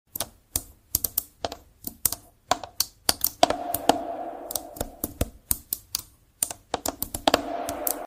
Glass keyboard ASMR is so sound effects free download
Glass keyboard ASMR is so satisfying